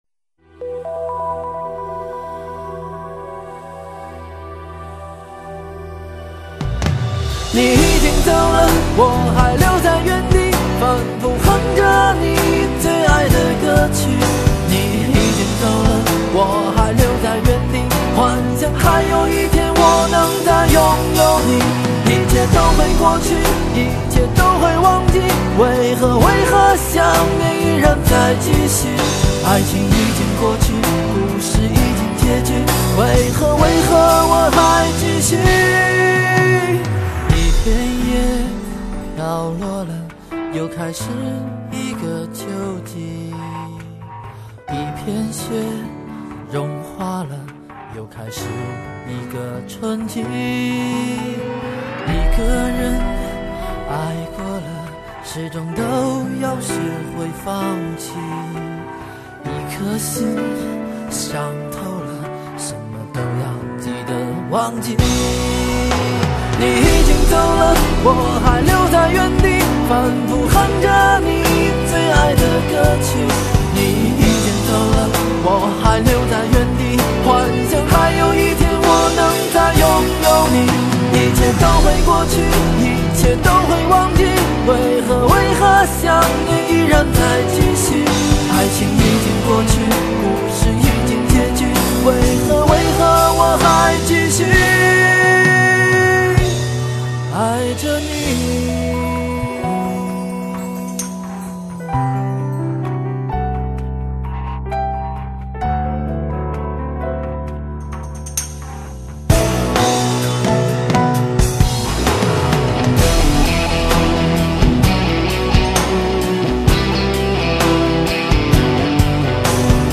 吉它